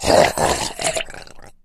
zombie_die_6.ogg